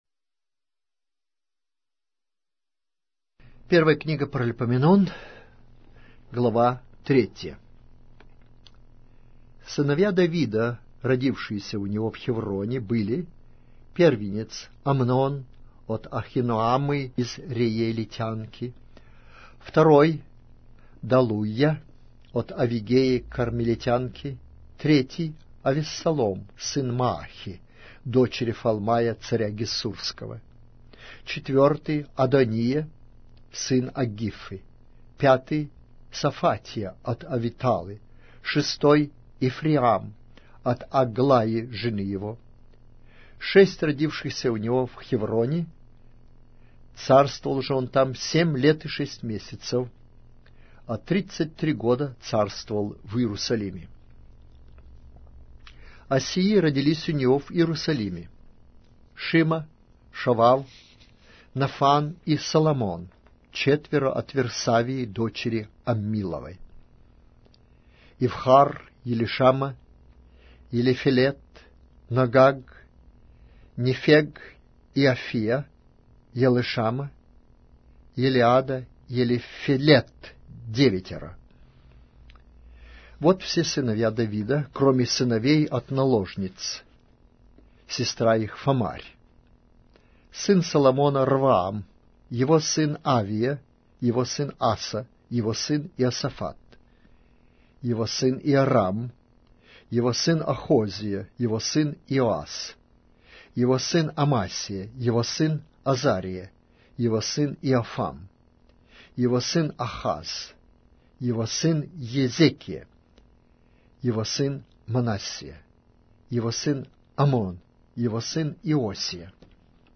Аудиокнига: 1-я Книга Паралипоменон